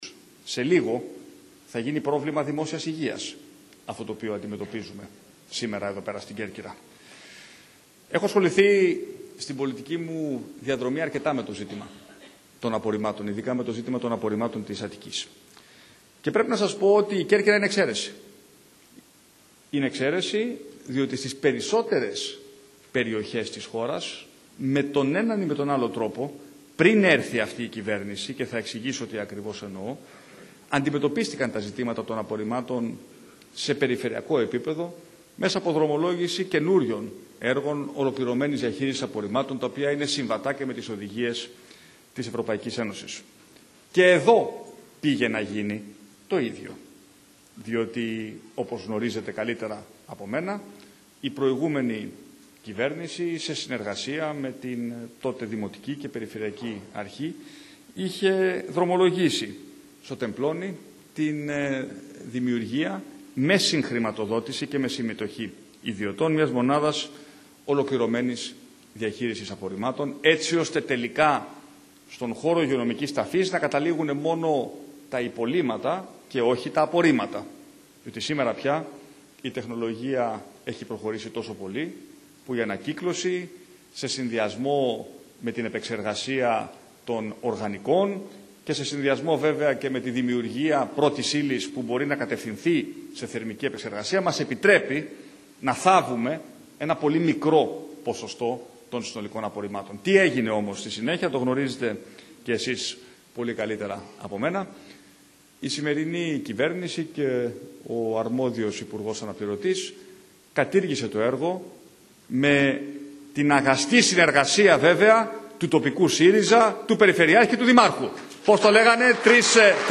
Ολοκληρώθηκε πριν από λίγο, σε κεντρικό ξενοδοχείο της Κέρκυρας, το αναπτυξιακό forum της Νέας Δημοκρατίας για τα Ιόνια Νησιά, παρουσία του προέδρου του κόμματος Κυριάκου Μητσοτάκη.
Ακούστε τη σχετική δήλωση του κ. Κ. Μητσοτάκη για τη διαχείριση των απορριμμάτων στο ηχογραφημένο που ακολουθεί:
ΑΠΟΡΡΙΜΜΑΤΑ-ΜΗΤΣΟΤΑΚΗΣ-ΣΥΝΕΔΡΙΟ-ΝΔ-ΚΕΡΚΥΡΑ.mp3